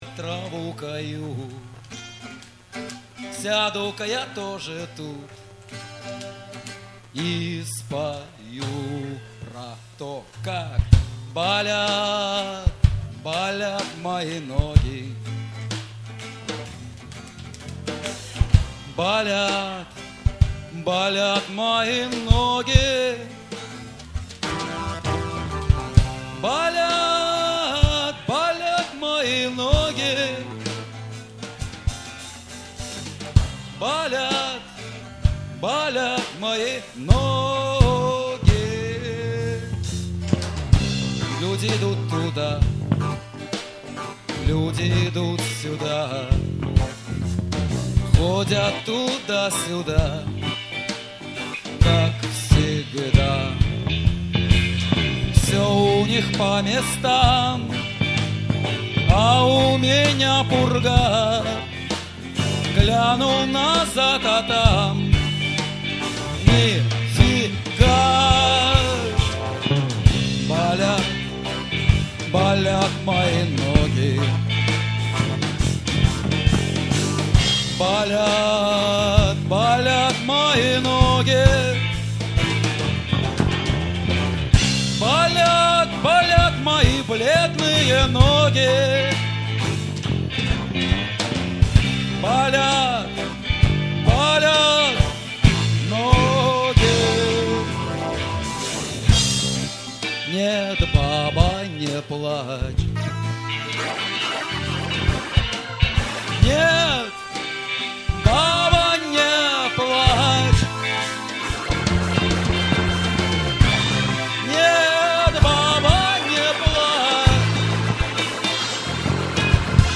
Концерт в "Орландине", 09.07.2007